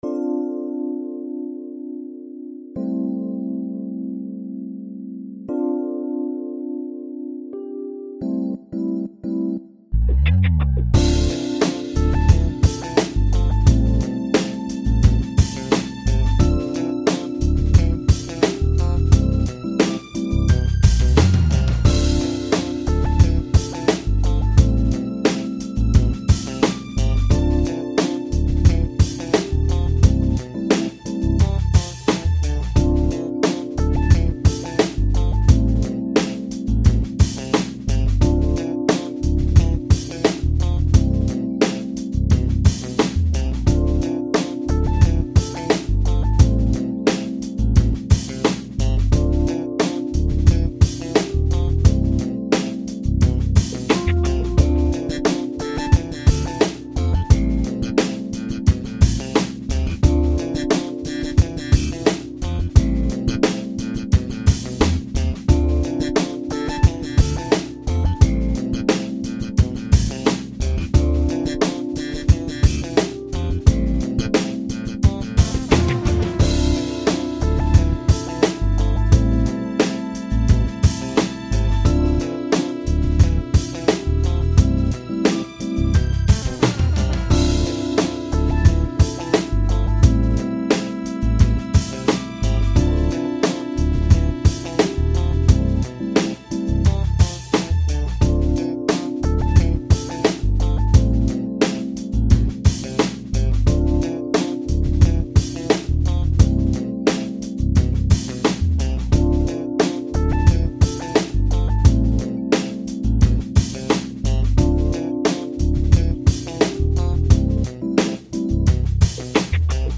neo soul? urban?